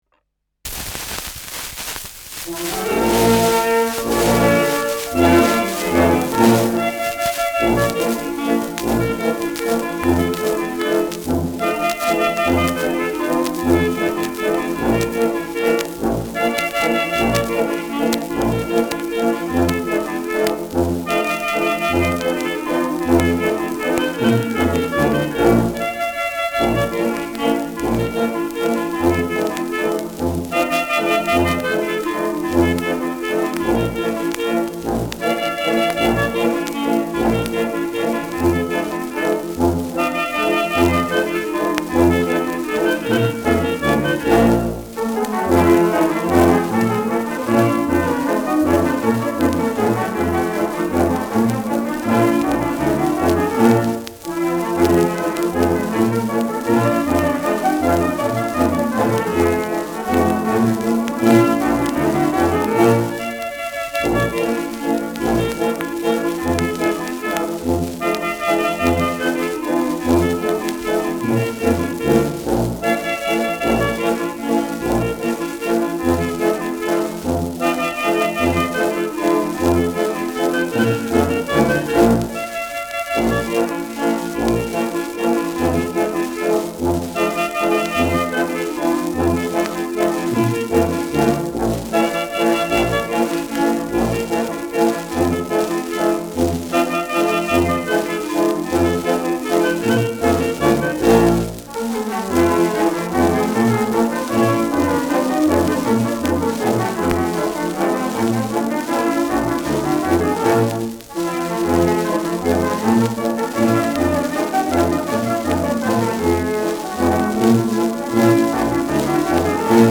Schellackplatte
leichtes bis präsentes Rauschen : präsentes Nadelgeräusch : leichtes Knistern
[München] (Aufnahmeort)